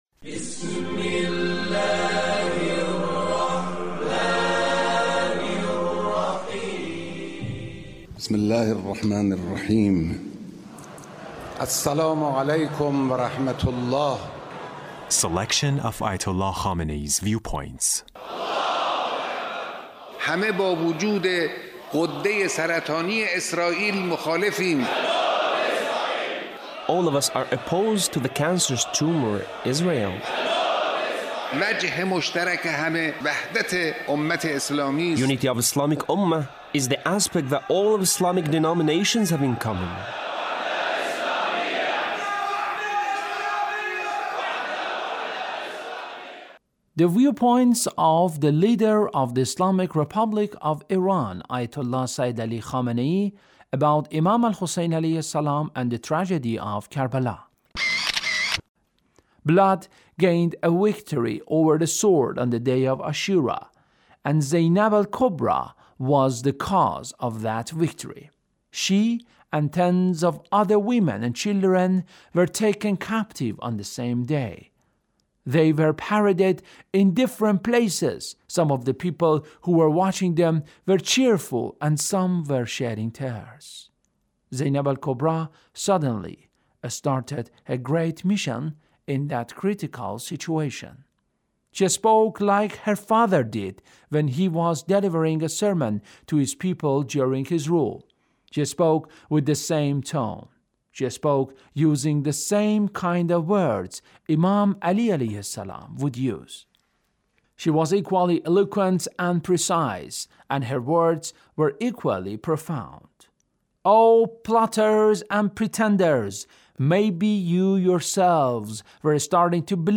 Leader's Speech (1778)